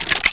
bow_reload_01.wav